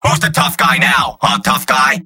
The Scout-bot on the weaknesses of the flesh